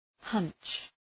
Προφορά
{hʌntʃ}
hunch.mp3